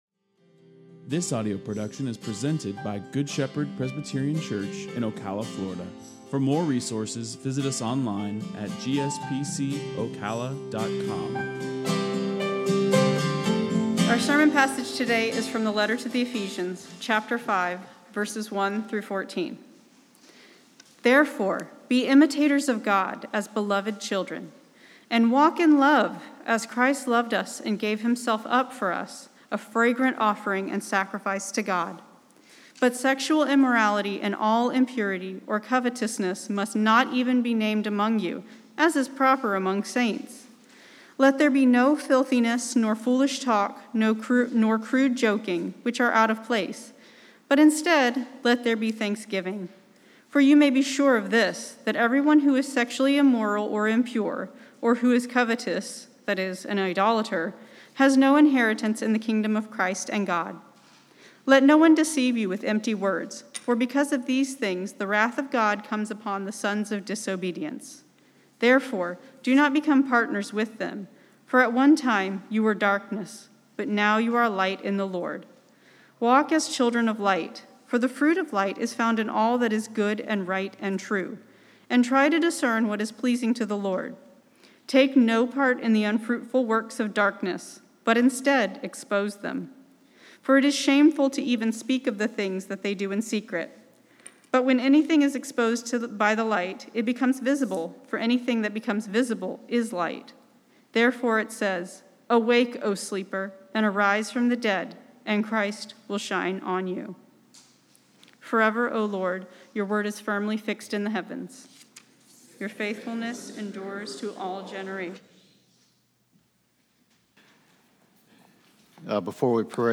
Sermon Audio 10/18/20 – Letter to the Ephesians Series: Ephesians 5:1-6